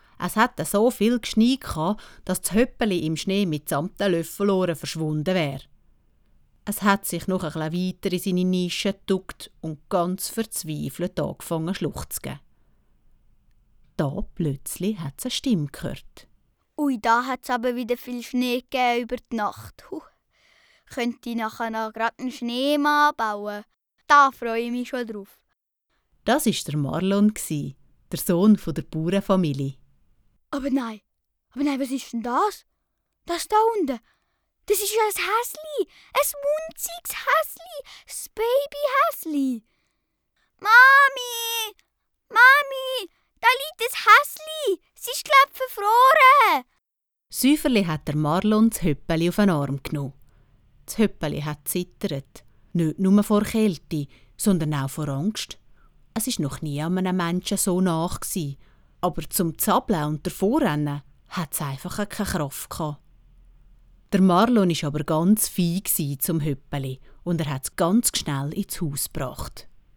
Osterhörspiel Dialekt